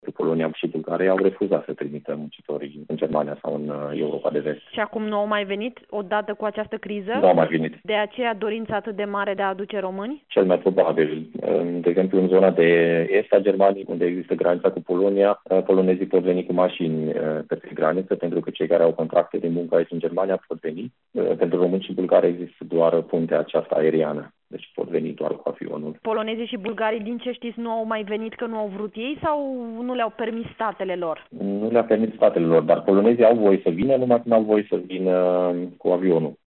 El spune, într-un interviu acordat Europa FM, că polonezii mai intră în Germania doar dacă lucrează aproape de graniță.